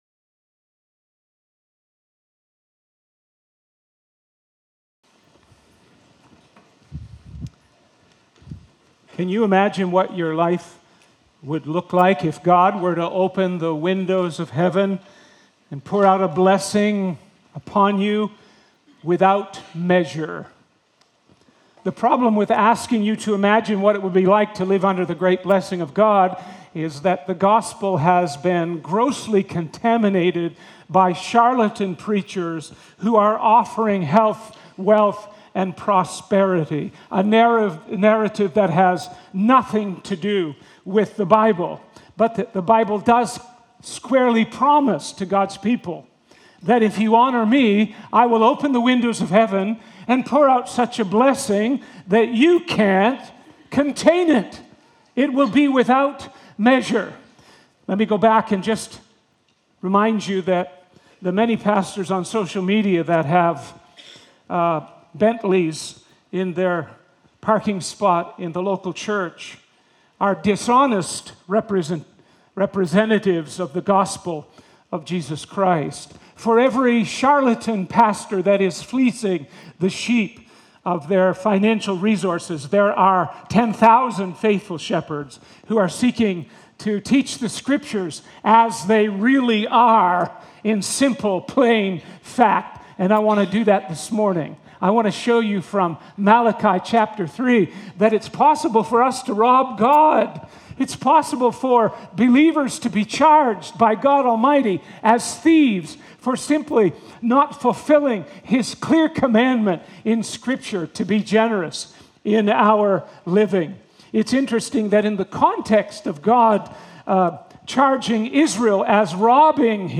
City Centre Church - Mississauga